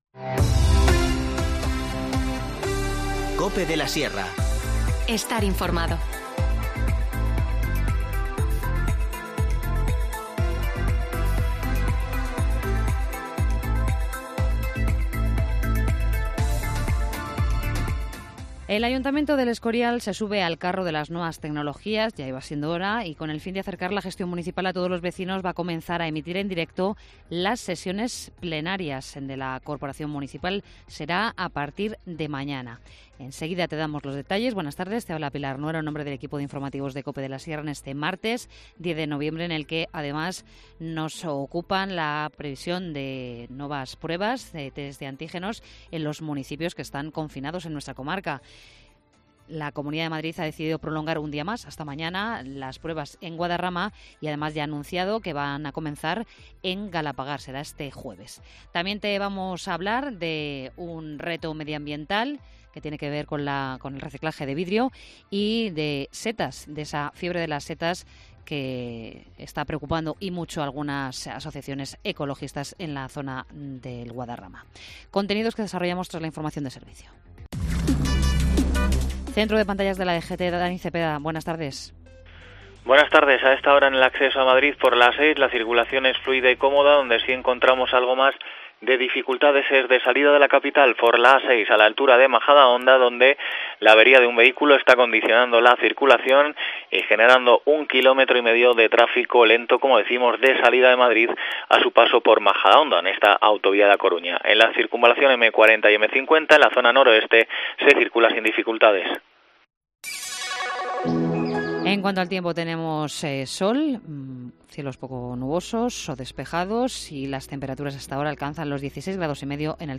Informativo Mediodía 10 noviembre